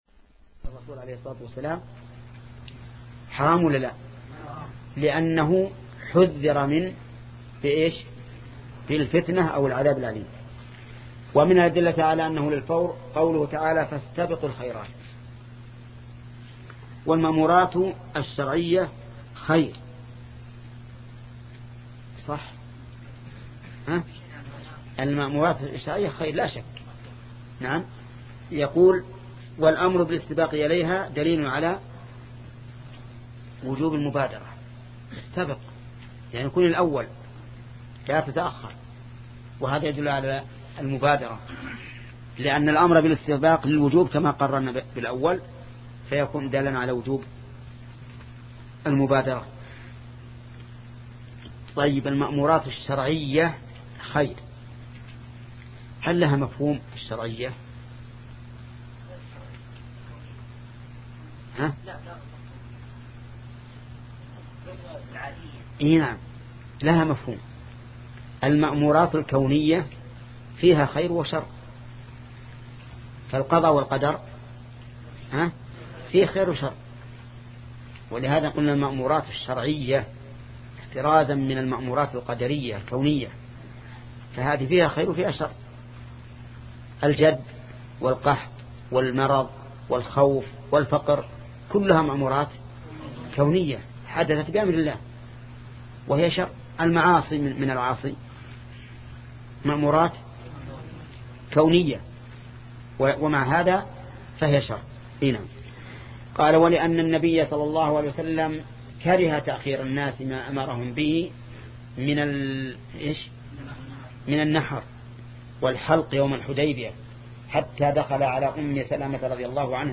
شبكة المعرفة الإسلامية | الدروس | الأصول من علم الأصول 5 |محمد بن صالح العثيمين